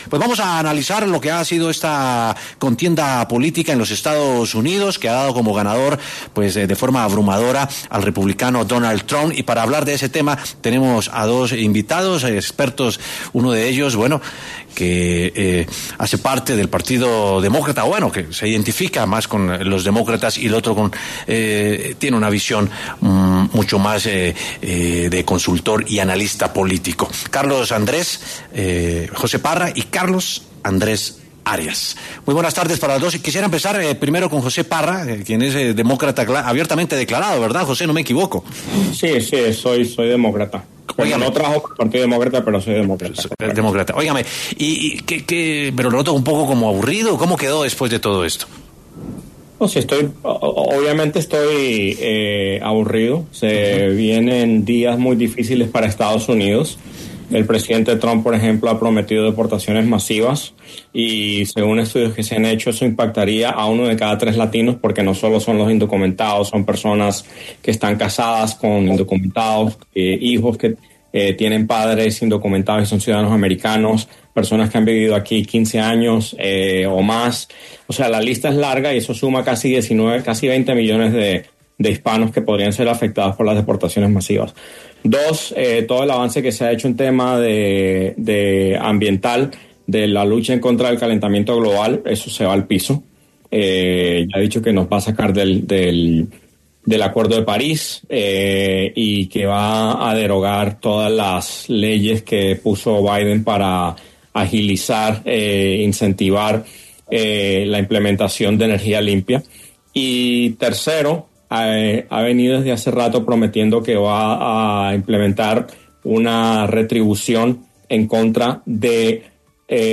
Debate: ¿Cuáles serán las medidas de Trump en contra de la migración ilegal?
Con el resultado de las elecciones presidenciales en Estados Unidos definido, dejando a Donald Trump como el presidente electo, W Sin Carreta consultó con analistas políticos sobre el impacto que esta nueva administración tendrá en los migrantes en ese país.